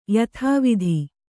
♪ yathāvidhi